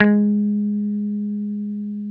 Index of /90_sSampleCDs/Roland L-CDX-01/GTR_Dan Electro/GTR_Dan-O 6 Str